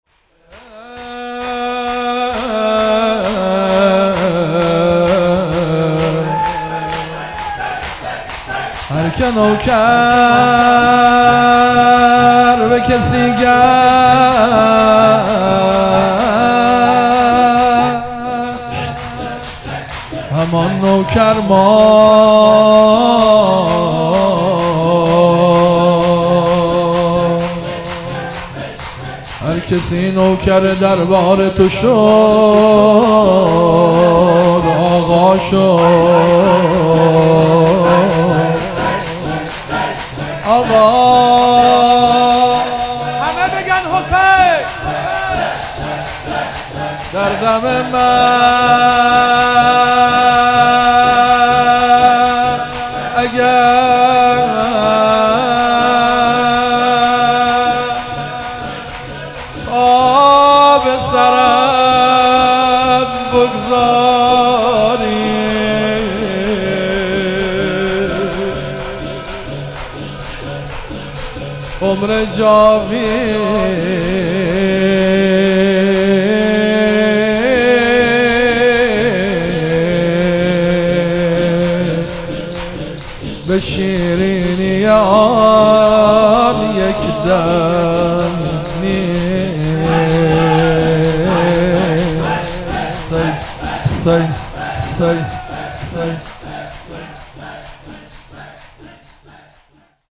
ذکر و نوا - هر که نوکر به کسی گشت همان نوکر ماند
مداحی
قرار هفتگی مسجد جامع یزد